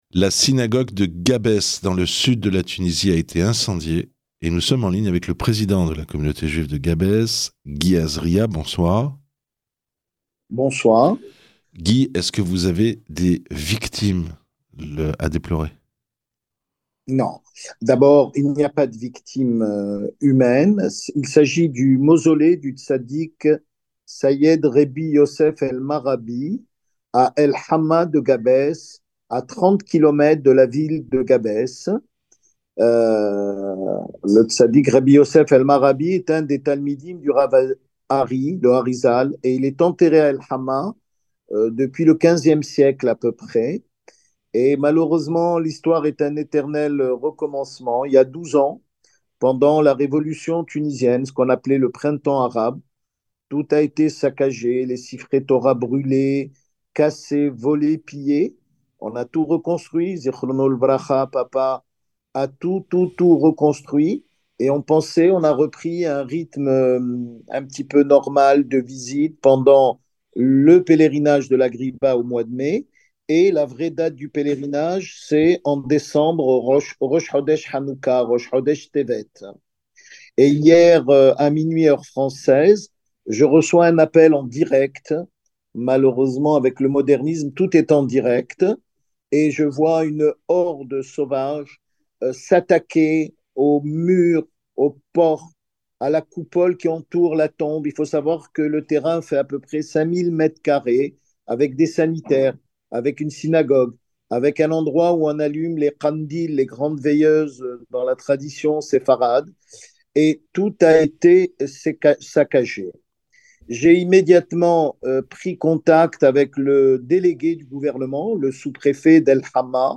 Ecoutez cet interview poignante